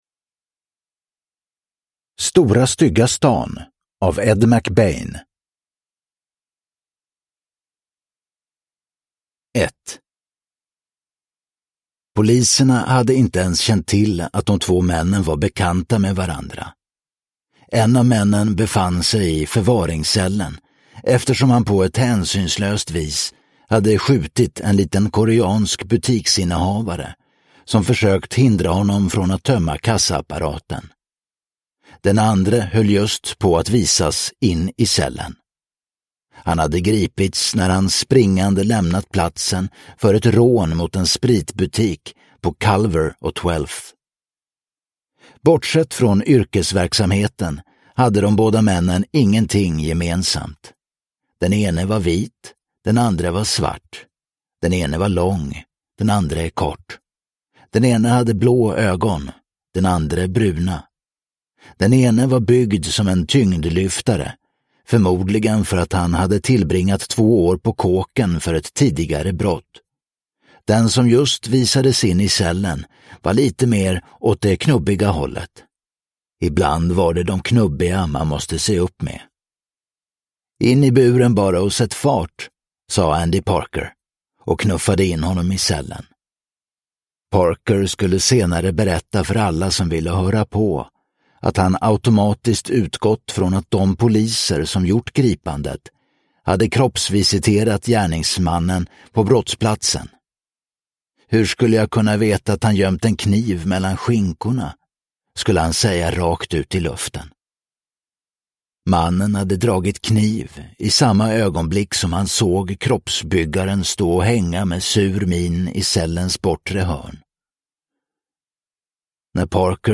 Stora stygga stan – Ljudbok – Laddas ner